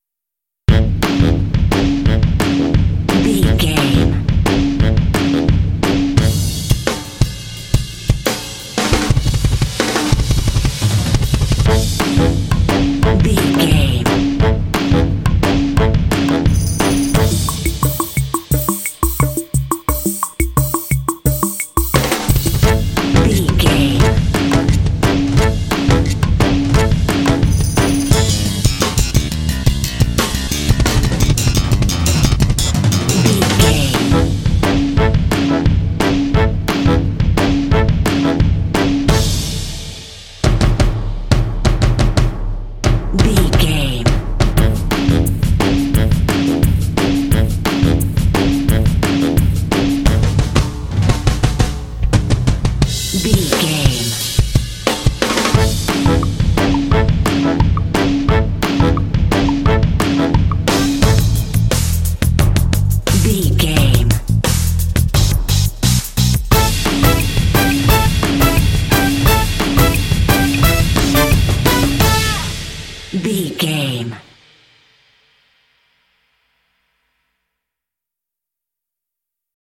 Aeolian/Minor
fun
happy
bouncy
groovy
brass
saxophone
quirky
kitschy